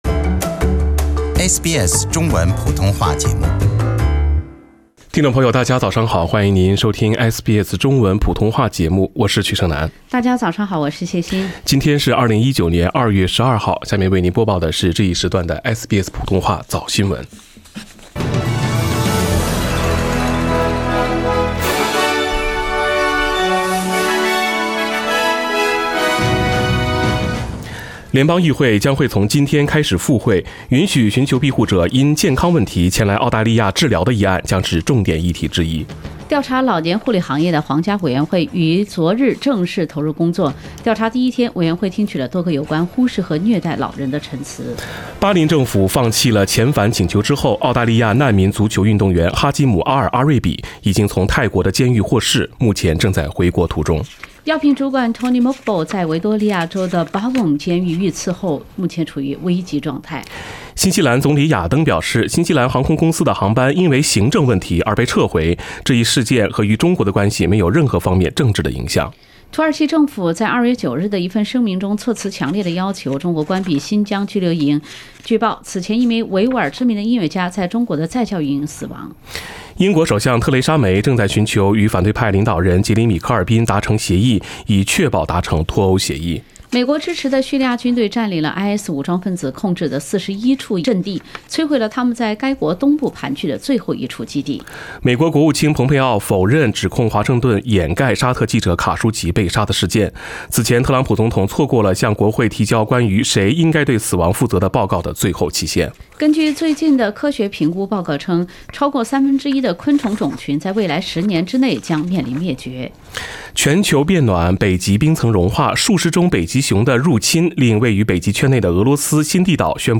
SBS Chinese Morning News Source: Shutterstock